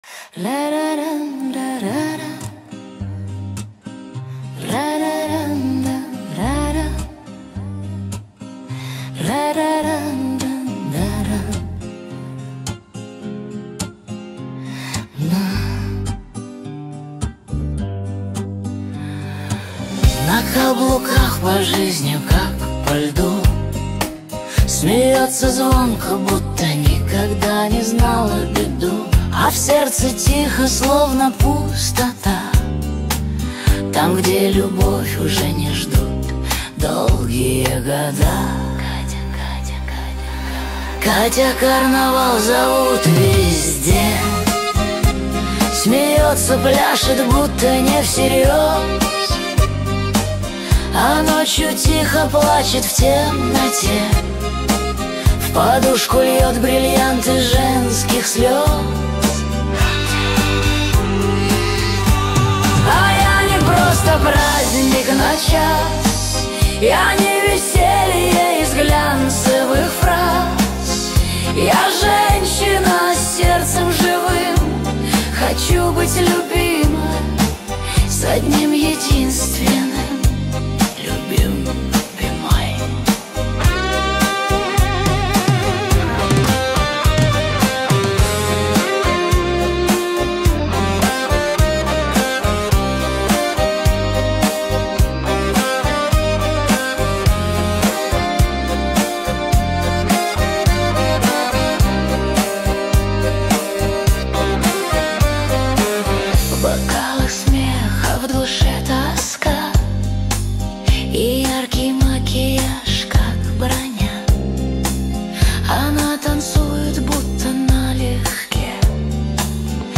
ШАНСОН о любви и судьбе